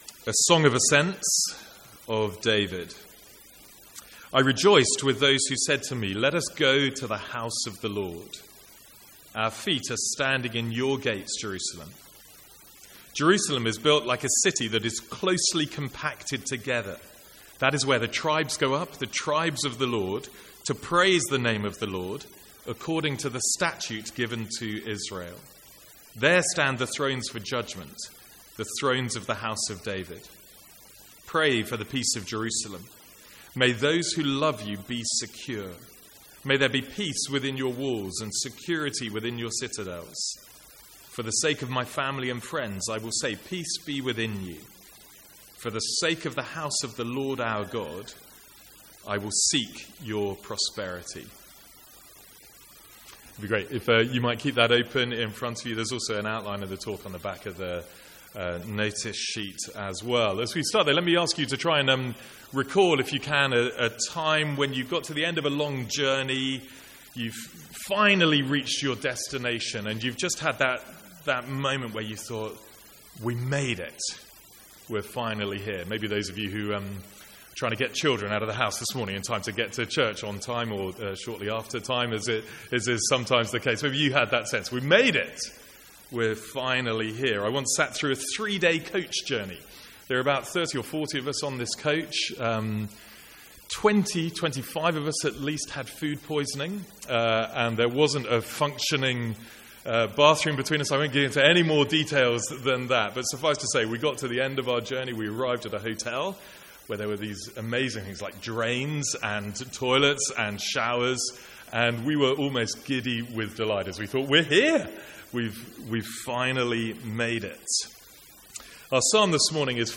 Sermons | St Andrews Free Church
From the Sunday morning series in the Psalms.